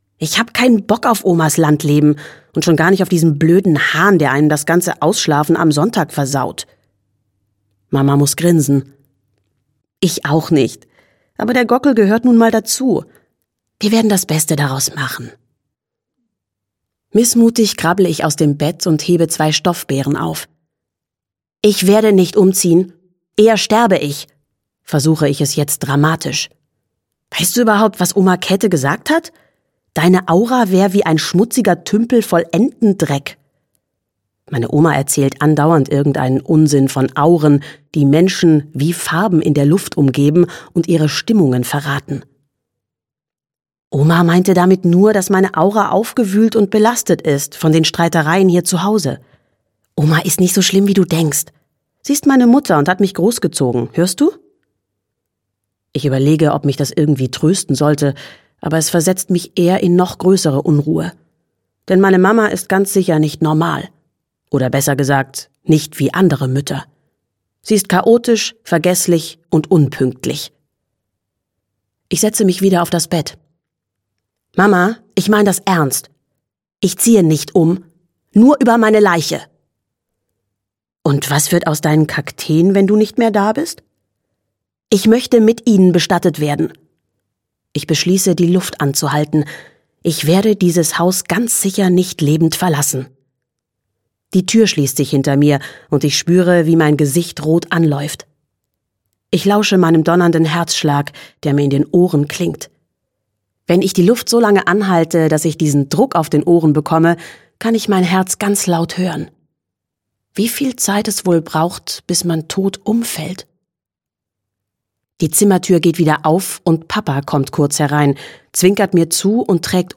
Ich wollt, ich wär ein Kaktus - Mina Teichert - Hörbuch